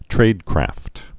(trādkrăft)